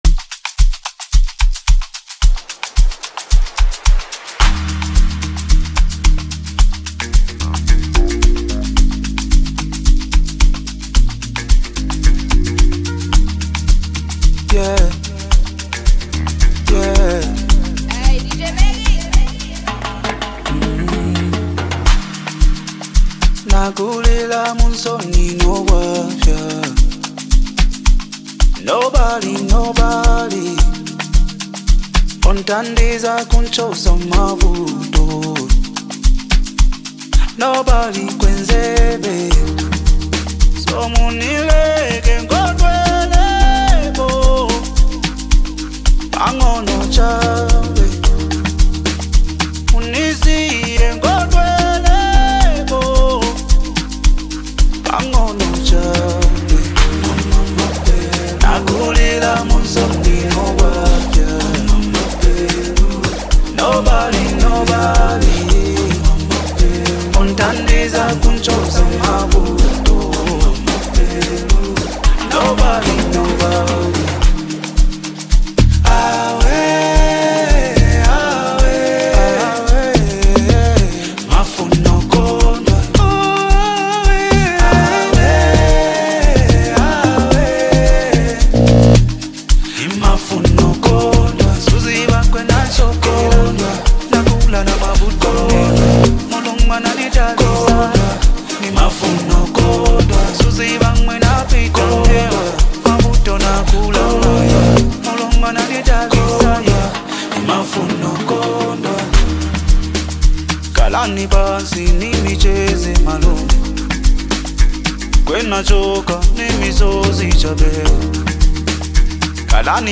with his deep roots in Zambian music and soulful delivery